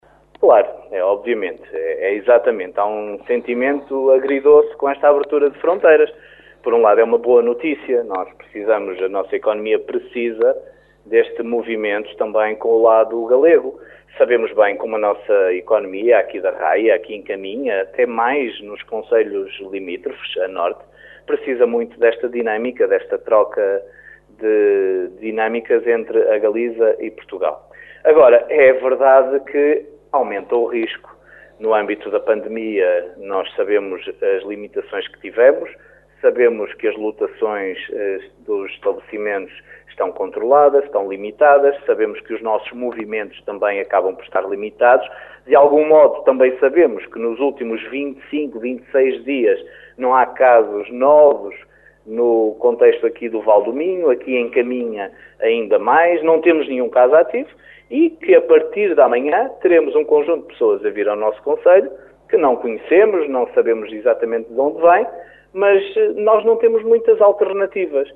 A abertura das fronteiras é uma boa notícia mas Miguel Alves lembra que a partir de agora as responsabilidades também são maiores. O autarca fala mesmo de um sentimento agridoce.